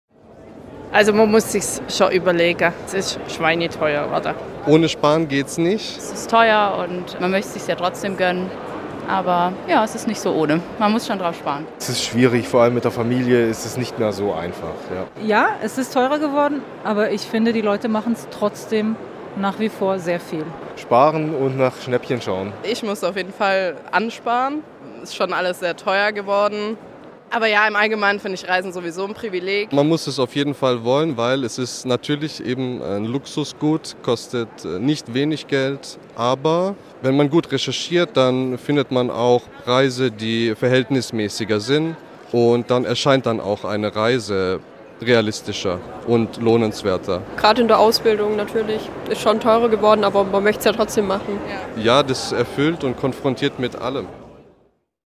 Umfrage
umfrage-ist-reisen-luxus-geworden.mp3